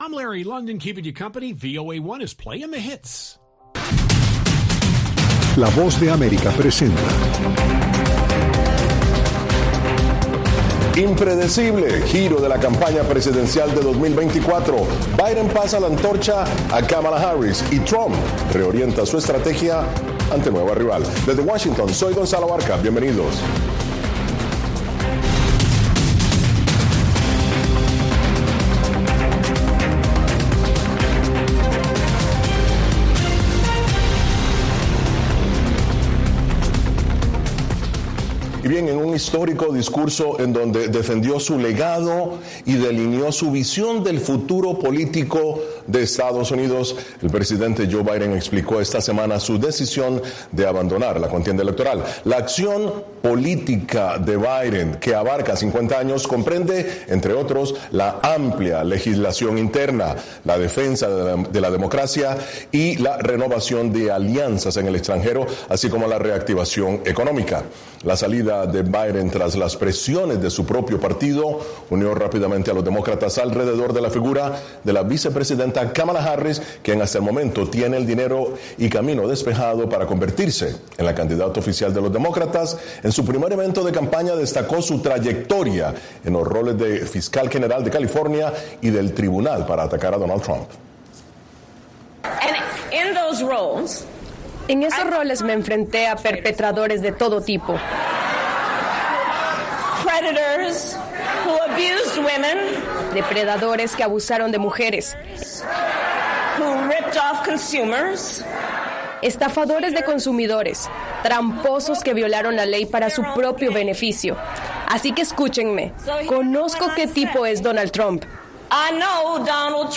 ¿Cómo se acomoda la campaña de Trump ante la nueva rival? Los expertos debaten.